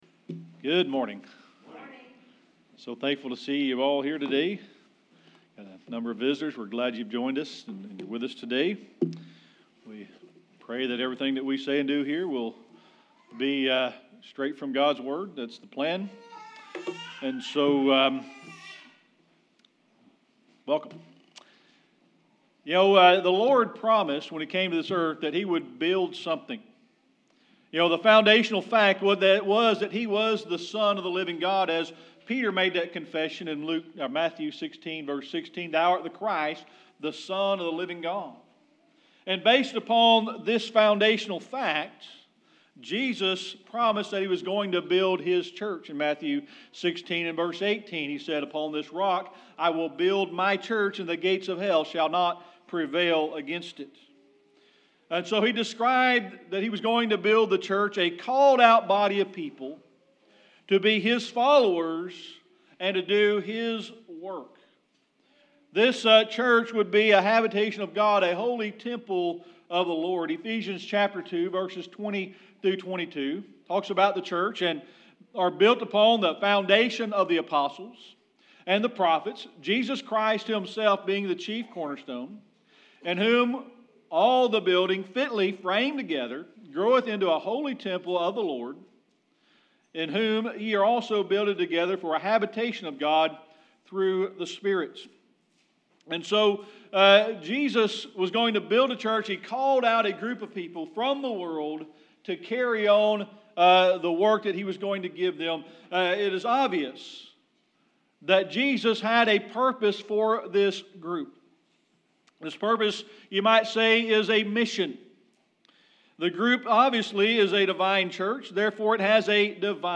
Sermon Archives
Passage: Ephesians 2:20-22 Service Type: Sunday Morning Worship The Lord promised when he came to the earth that he would build something.